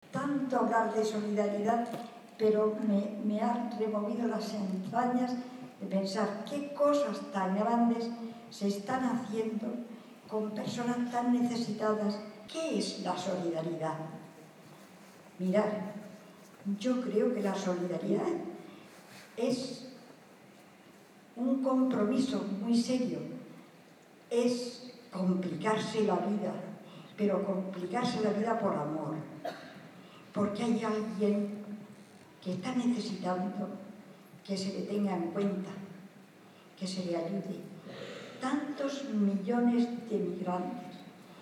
Apenas una semana después, el martes 8 de noviembre, era el Teatro Circo de Murcia el que servía de escenario a la gala de entrega de los Premios Solidarios ONCE-Murcia, 2016.